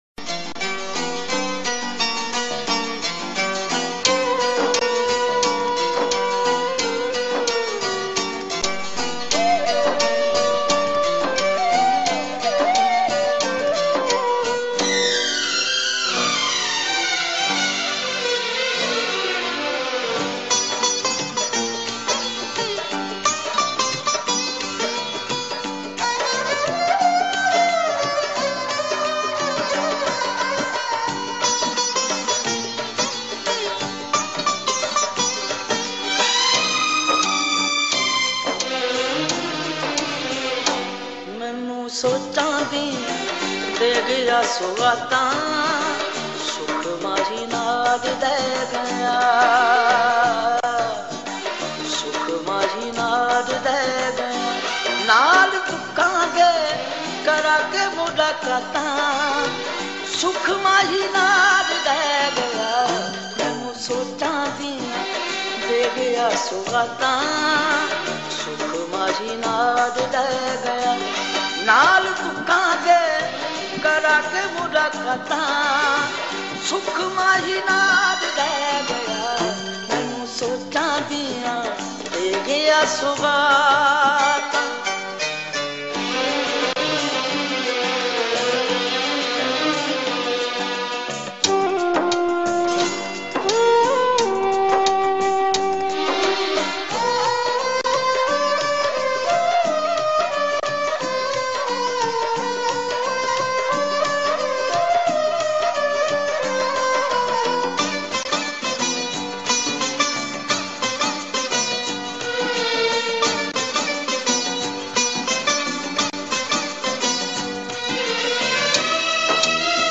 Punjabi love song
and with movie playback